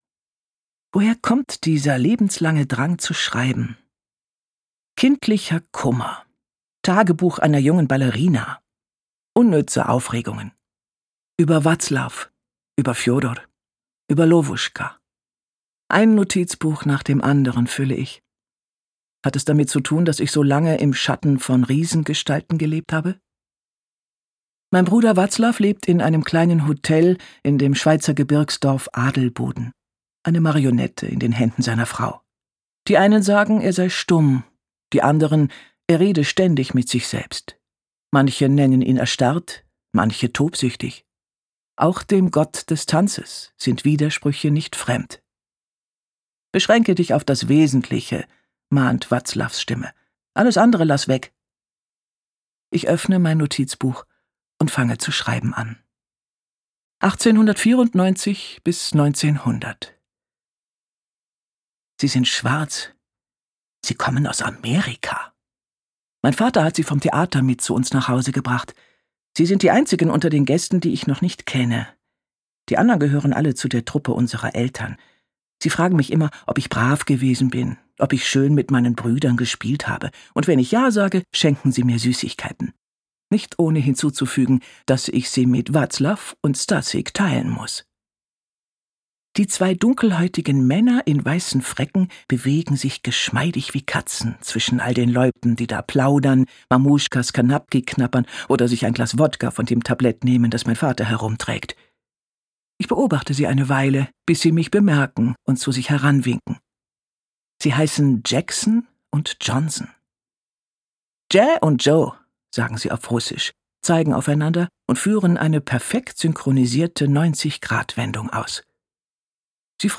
Länge Gekürzte Lesung, 8 CDs, Laufzeit ca. 600 Min.